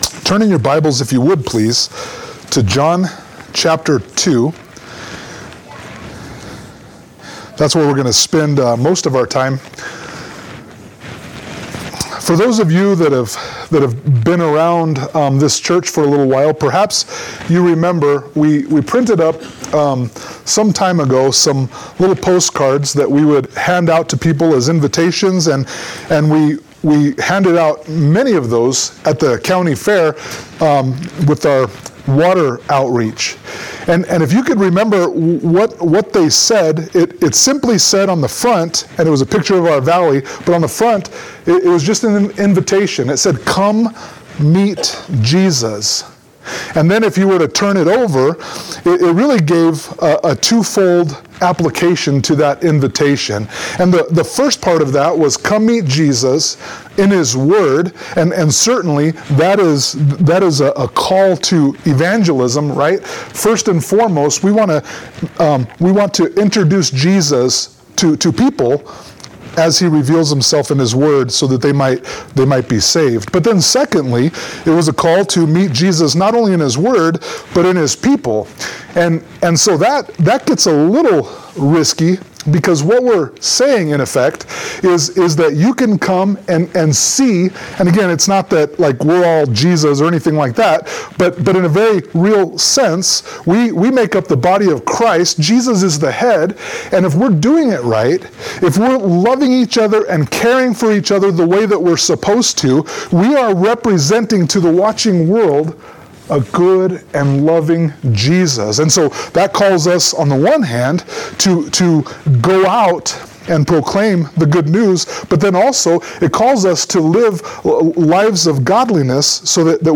John 2:1-12 Service Type: Sunday Morning Worship « John 1:19-34 John 2:13-25